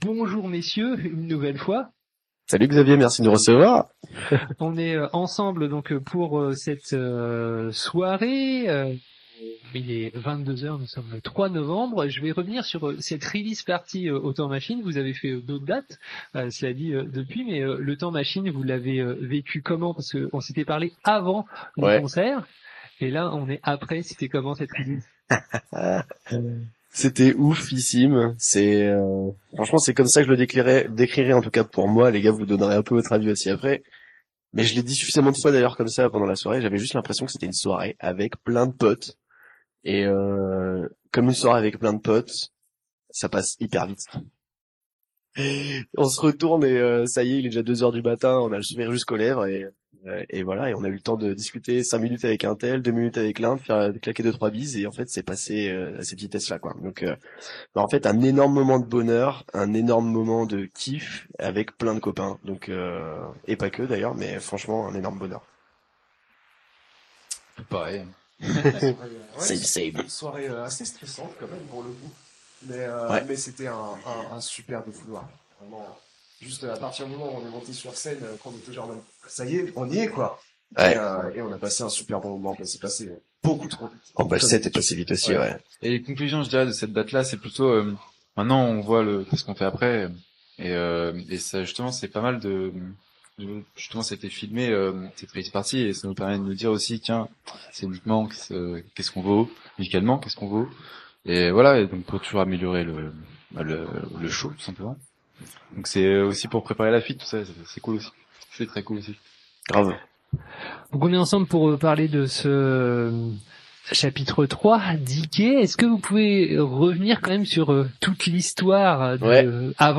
Interview enregistrée lors du passage dans les studios du groupe Yerao le 3.11.2025 :